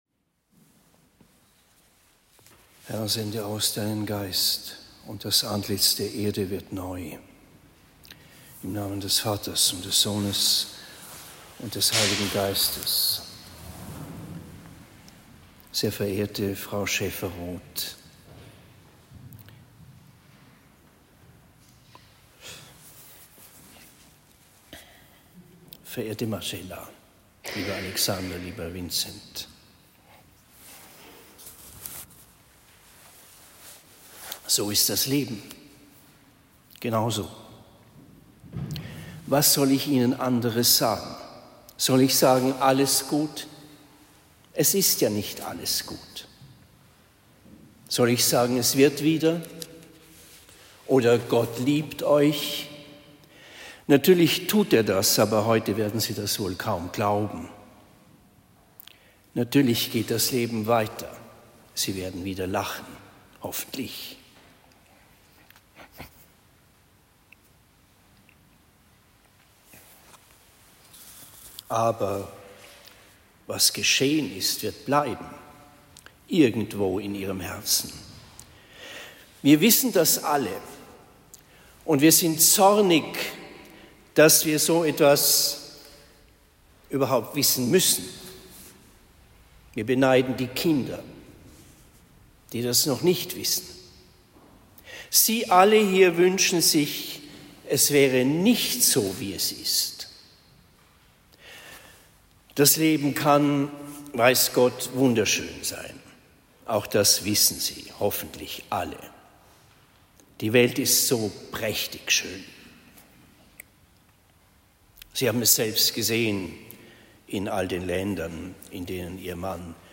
Predigt in Marktheidenfeld St.-Laurentius am 24. April 2024